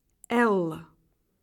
En cliquant sur le symbole, vous entendrez le nom de la lettre.
lettre-l.ogg